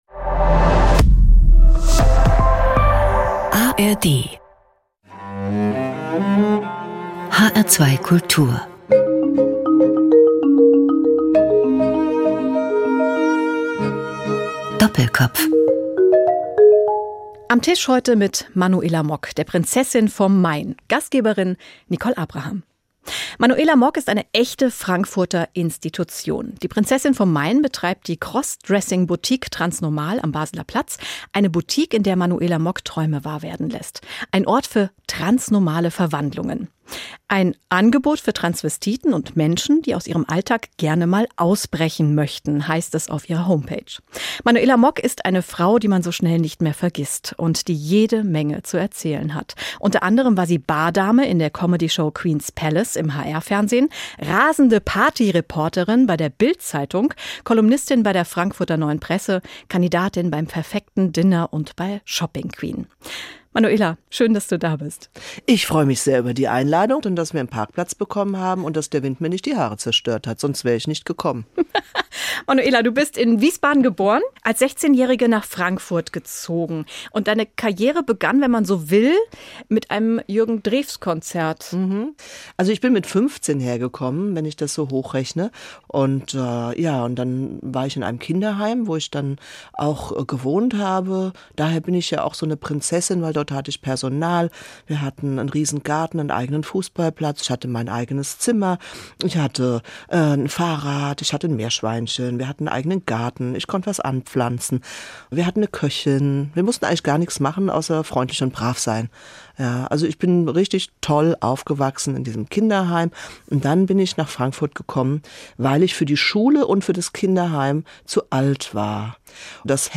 Interessante Zeitgenossen - Menschen, die etwas zu sagen haben, unterhalten sich 50 Minuten lang mit einem Gastgeber über ihre Arbeit und ihr Leben.